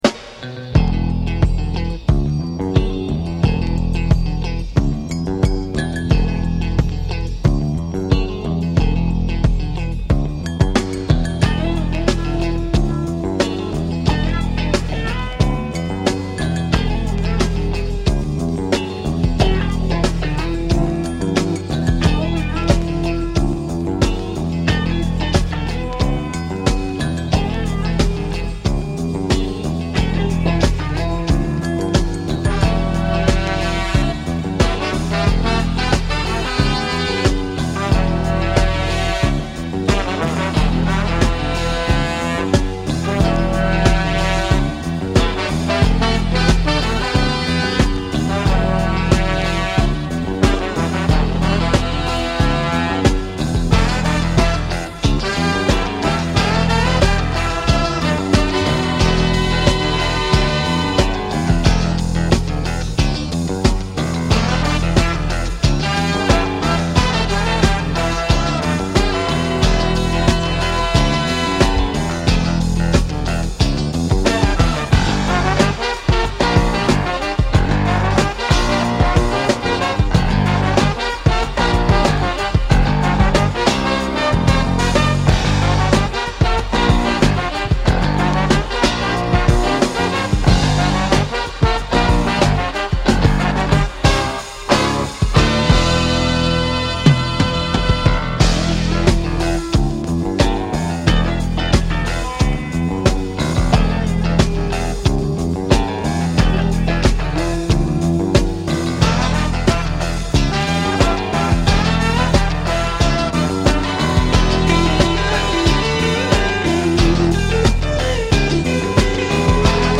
drums
keyboards and percussion
bass
guitar